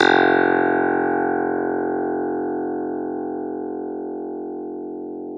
CLV_ClavDBF_2 2d.wav